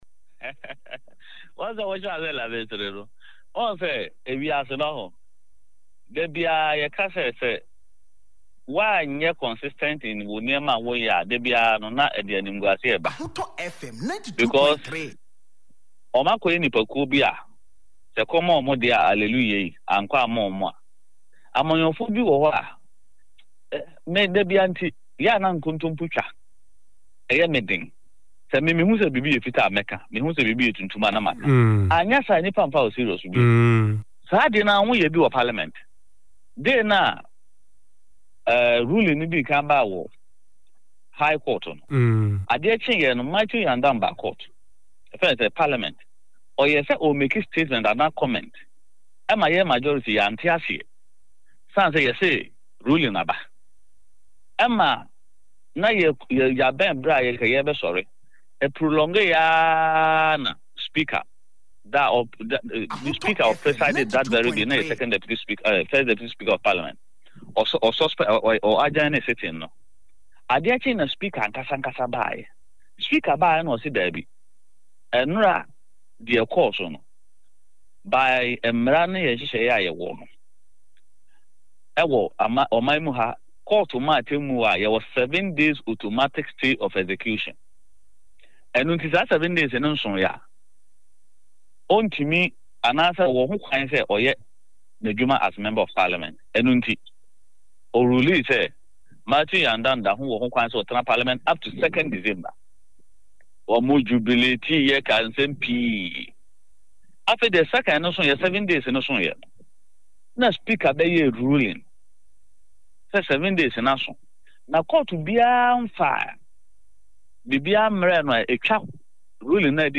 Speaking on the issue on Ahotor FM, he warned the NPP against selective acceptance of court rulings, citing their criticism of the Speaker of Parliament after declaring the Kpandai seat vacant. He emphasized that the NPP should respect the court’s decision, regardless of the outcome on January 13.